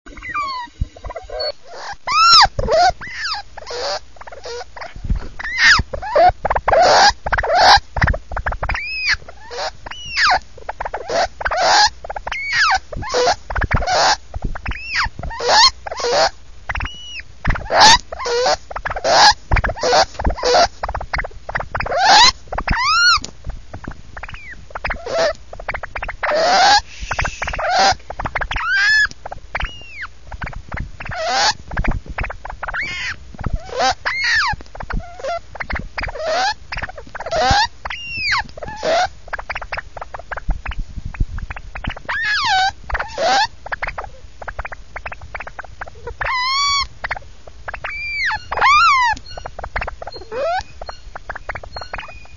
Sounds degus make
Pissed.wav While together my male and female degu got into a quarrel and this sound file shows the sound she made after I separated them. Fortunately they were not hurt, but boy were they ever mad.